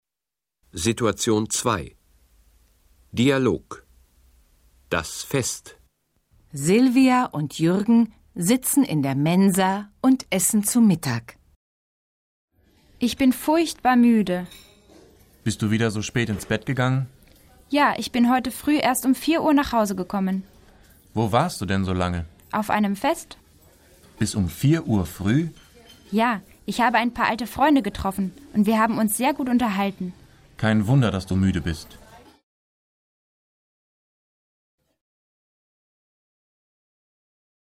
Situation 2 – Dialog: Das Fest (607.0K)